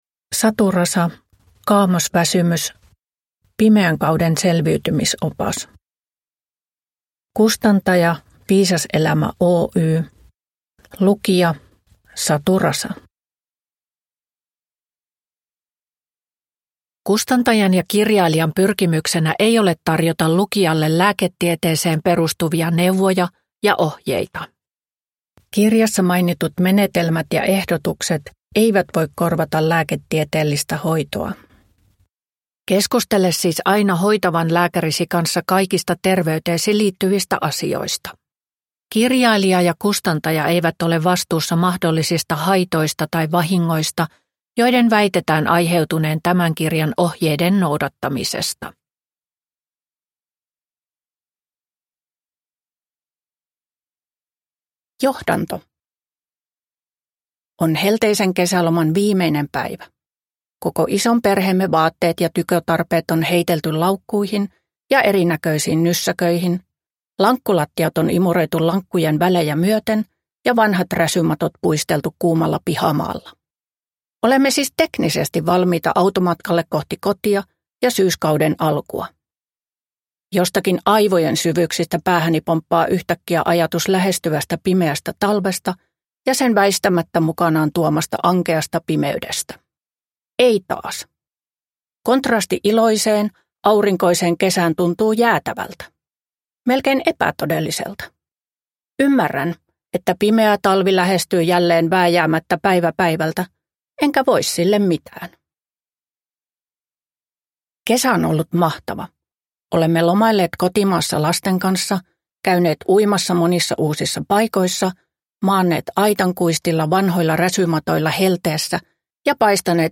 Kaamosväsymys – Ljudbok